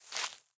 Minecraft Version Minecraft Version 25w18a Latest Release | Latest Snapshot 25w18a / assets / minecraft / sounds / block / big_dripleaf / tilt_up4.ogg Compare With Compare With Latest Release | Latest Snapshot
tilt_up4.ogg